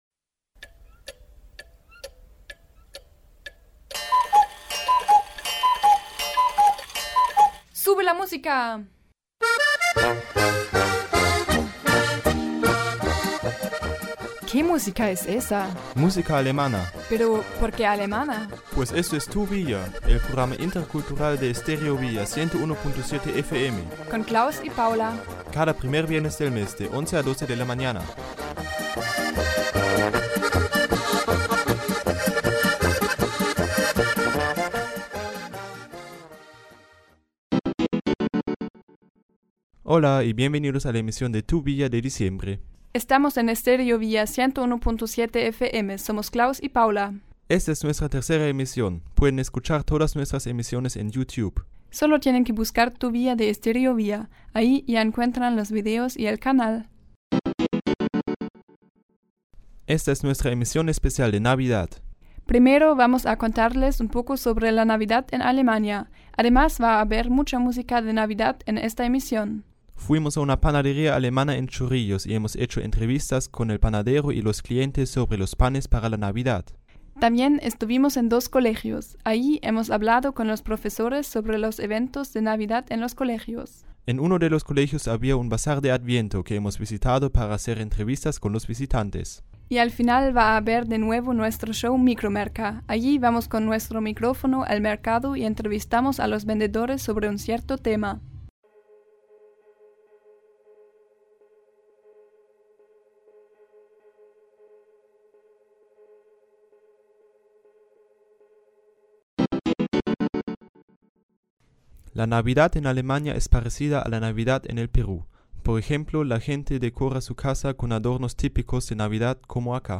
Entsprechend wurden Interviews und Umfragen in einer deutschen Bäckerei, auf einem Adventsbasar und in einer Schule aufgenommen.